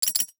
NOTIFICATION_Metal_03_mono.wav